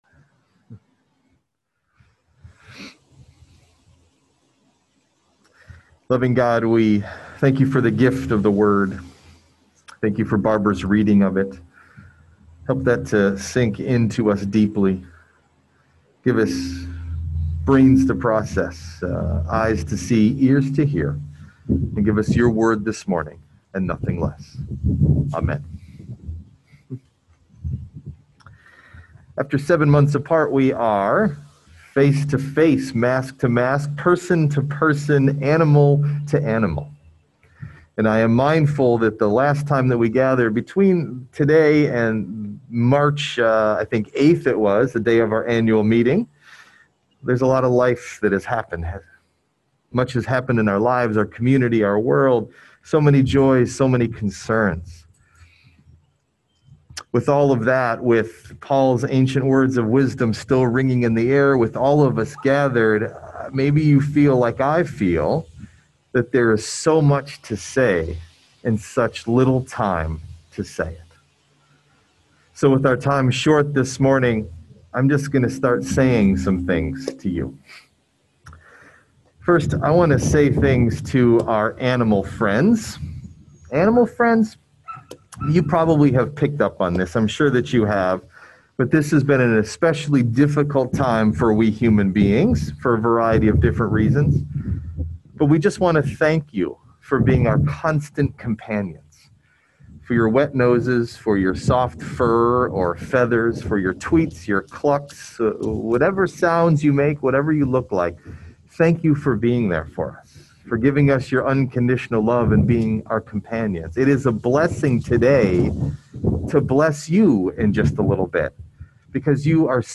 Date: Septemebr 13th, 2020 (Pentecost 15) Message Delivered at: Charlotte Congregational Church (UCC) Key Text(s): 1 Corinthians 12:1-11 This sermon was preached during our first in-person service since March 8th, 2020 (thanks Covid-19). We gathered on the lawn of our church to hear the Gospel and offer a Blessing of the Animals.
Message Delivered at: Charlotte Congregational Church (UCC)